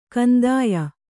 ♪ kandāya